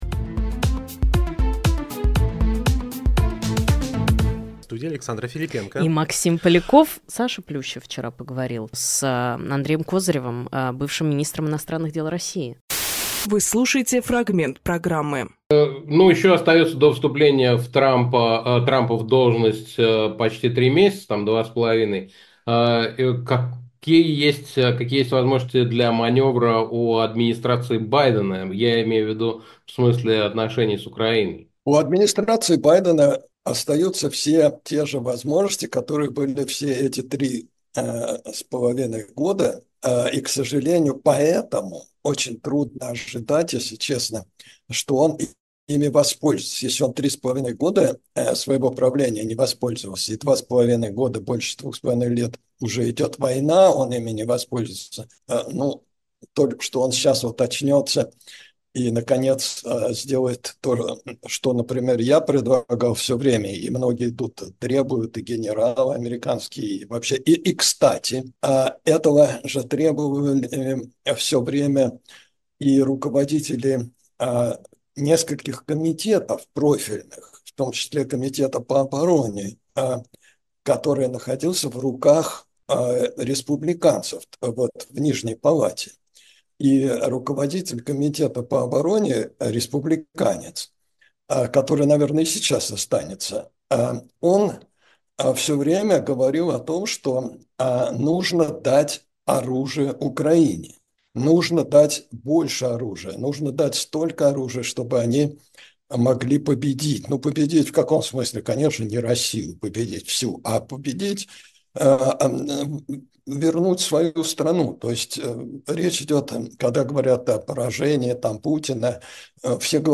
Фрагмент эфира от 10.11.24